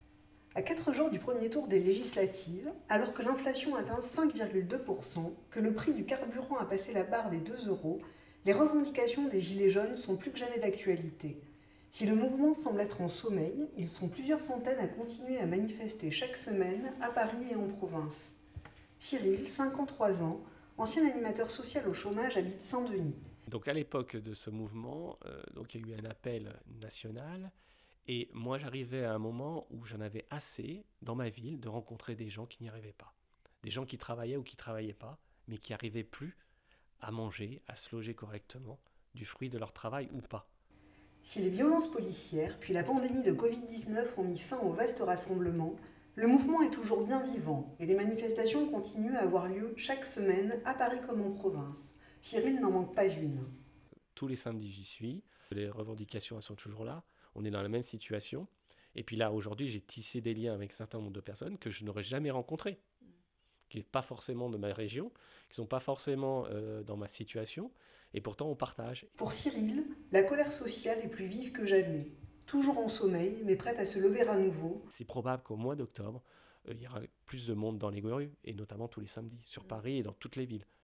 Interview audio d’un gilet jaune- Avril 2022
portrait-dun-gilet-jaune.mp3